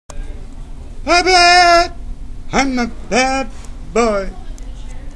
(THERE IS A FULL LIST OVER 70 IMPRESSIONS AT THE BOTTOM OF THE PAGE)
Why no Abbot it was so close to mine and flawless.
Costello_bad_boy.wma